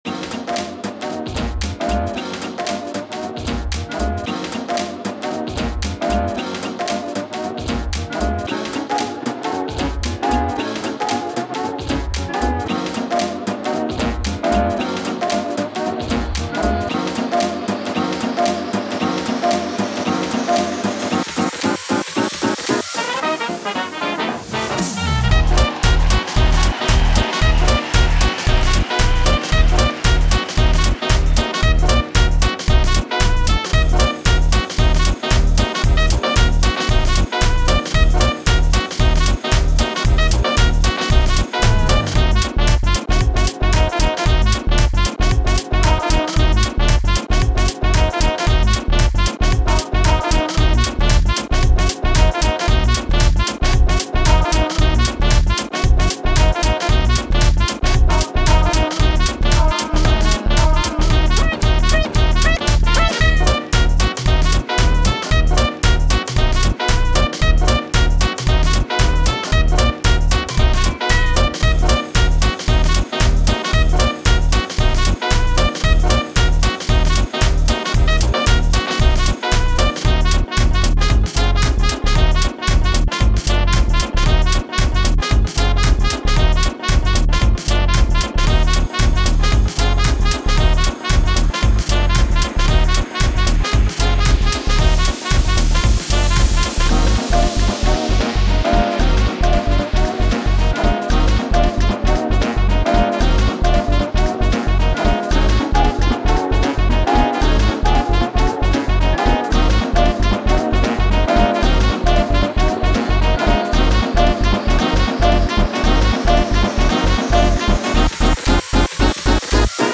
前回よりも細かくスライスしてフレーズを組み上げており、猫の声のサンプリングなども入れて遊んでいる。